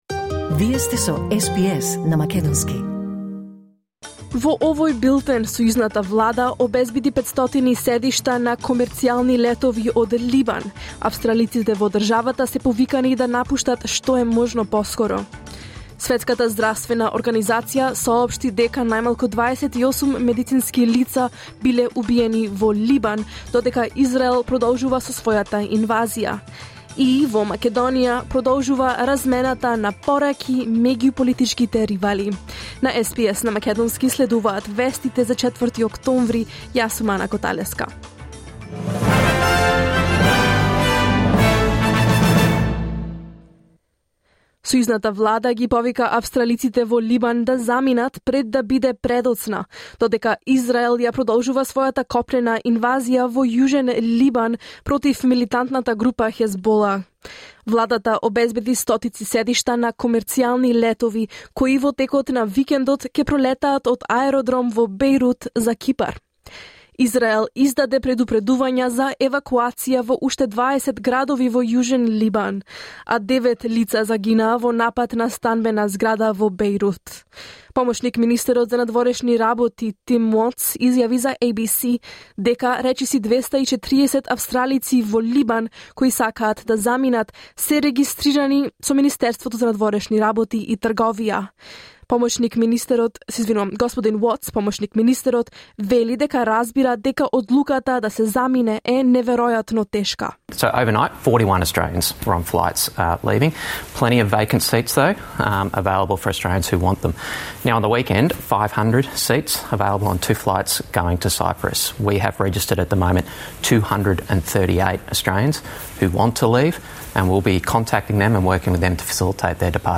SBS News in Macedonian 4 October 2024